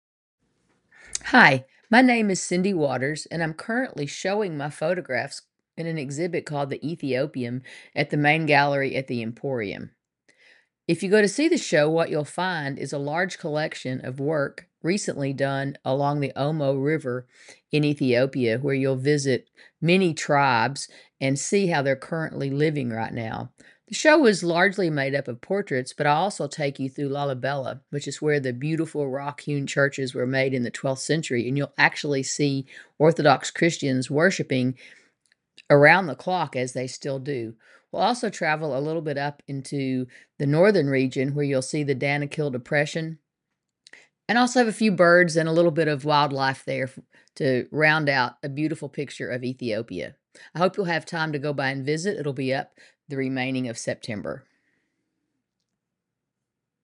Enjoy a welcome statement from the artist!